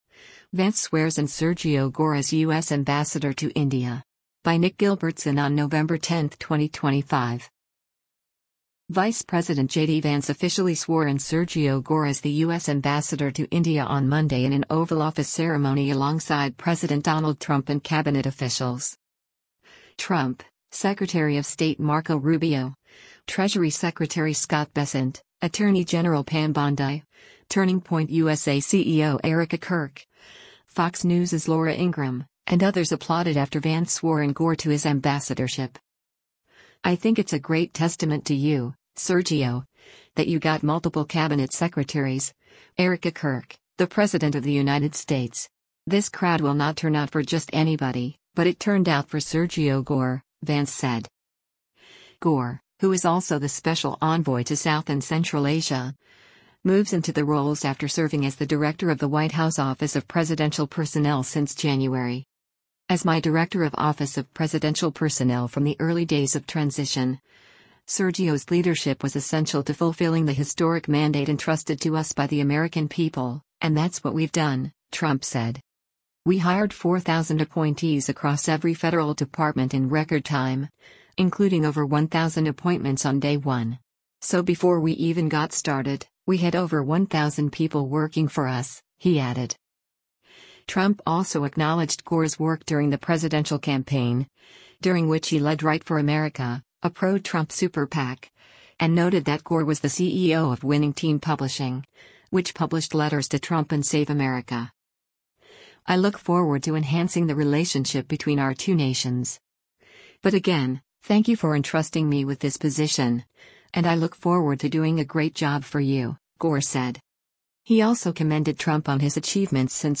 Vice President JD Vance officially swore in Sergio Gor as the U.S. Ambassador to India on Monday in an Oval Office ceremony alongside President Donald Trump and Cabinet officials.
Trump, Secretary of State Marco Rubio, Treasury Secretary Scott Bessent, Attorney General Pam Bondi, Turning Point USA CEO Erika Kirk, Fox News’s Laura Ingraham, and others applauded after Vance swore in Gor to his ambassadorship.